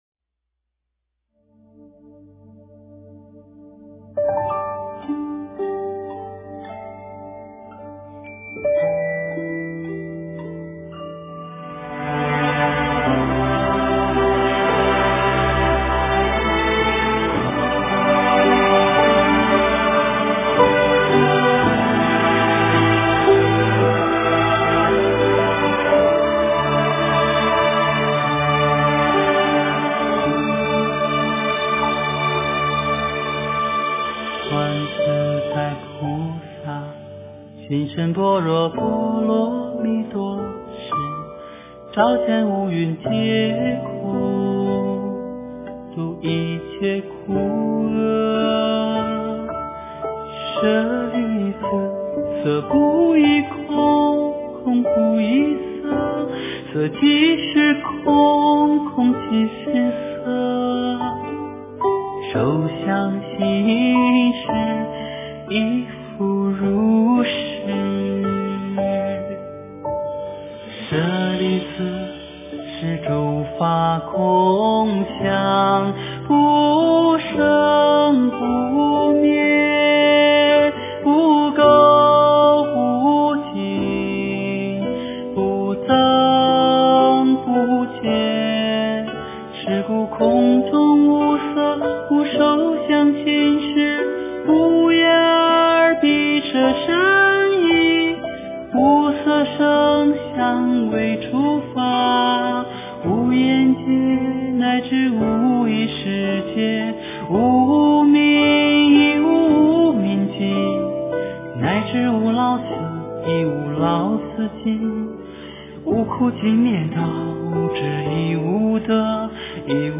诵经
佛音 诵经 佛教音乐 返回列表 上一篇： 三宝歌 下一篇： 心经 相关文章 三世因果歌--净土Pure Land 三世因果歌--净土Pure Land...